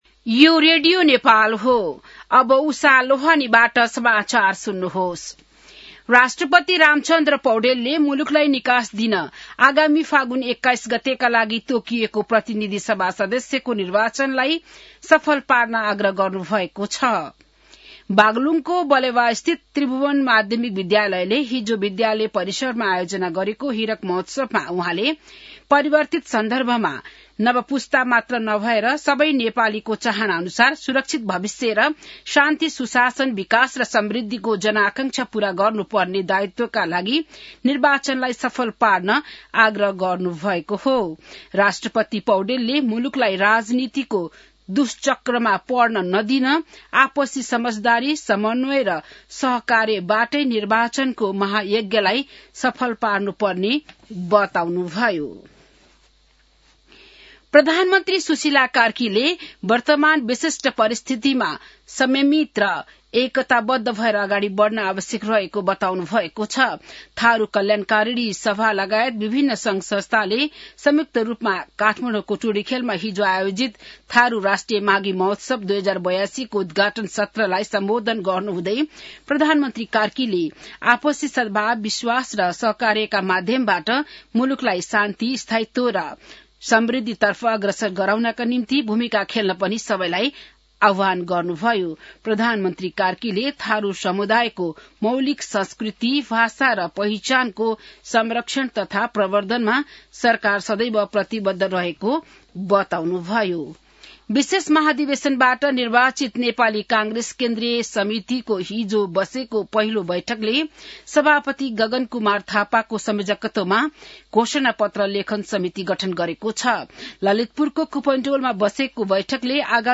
बिहान १० बजेको नेपाली समाचार : २ माघ , २०८२